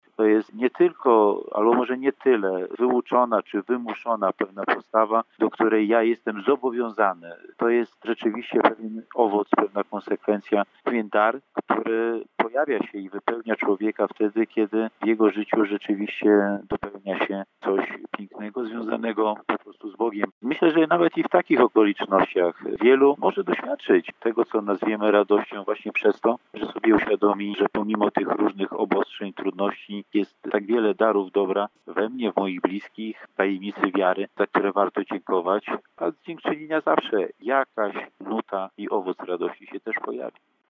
Biskup pomocniczy diecezji warszawsko-praskiej, Marek Solarczyk.